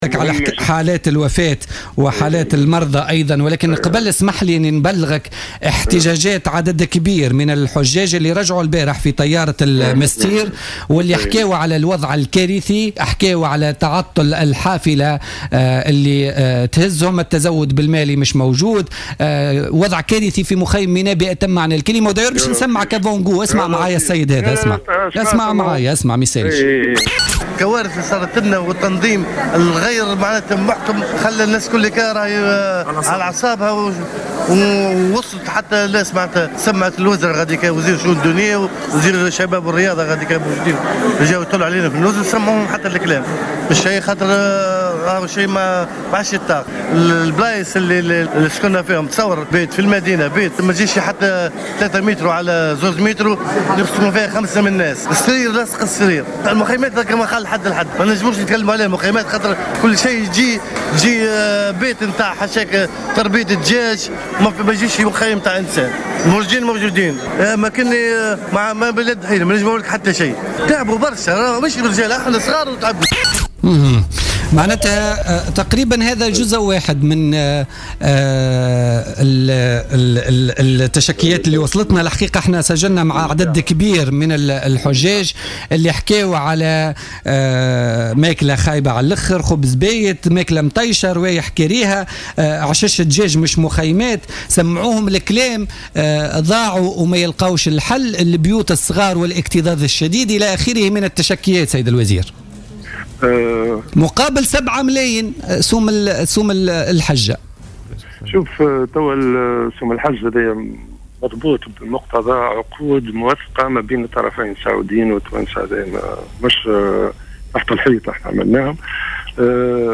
Le ministre des affaires religieuses, Othmen Battikh, est revenu lors de son intervention sur les ondes de Jawhara FM dans le cadre de l’émission Politica du mercredi 30 septembre 2015, sur le désagrément exprimé par certains pèlerins après leur arrivée en Tunisie.